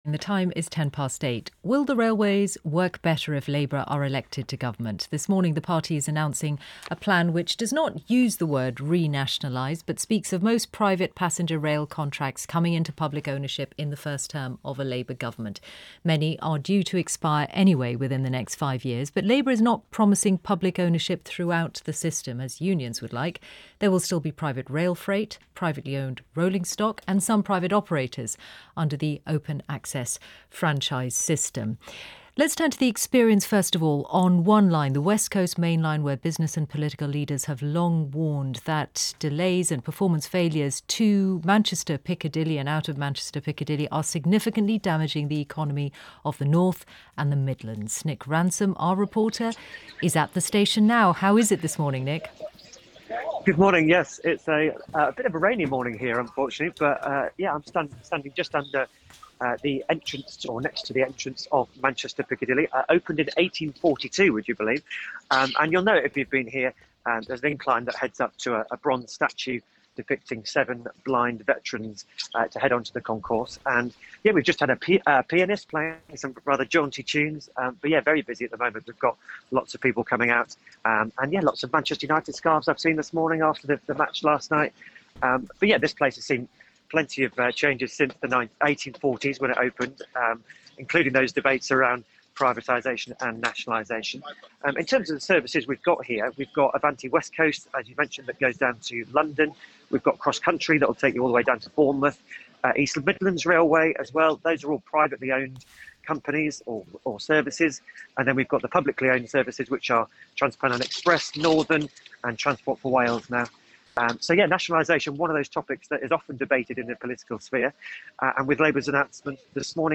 This morning, I reported on Radio 4's Today programme, exploring potential nationalisation of the railway from Manchester Piccadilly station.
In a first appearance on the esteemed speech-focused station, I set the scene of Manchester Piccadilly before introducing a series of views from members of the public that I'd recorded earlier.